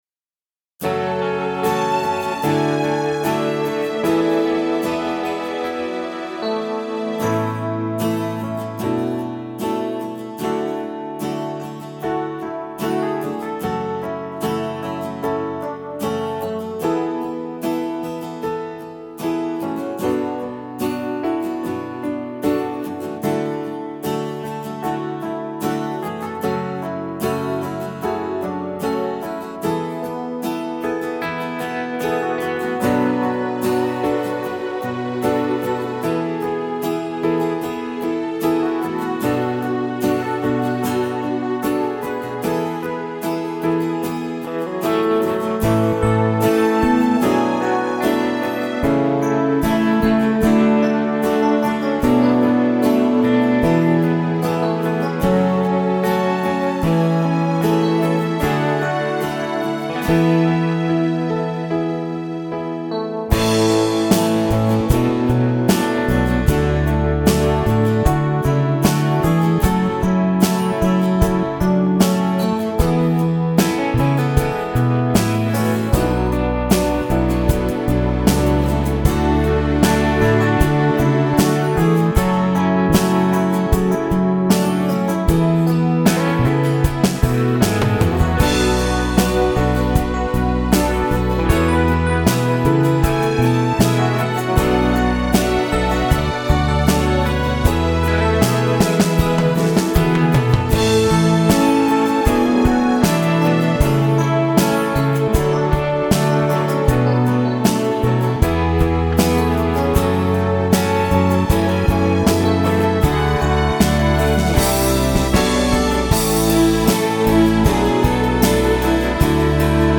Backing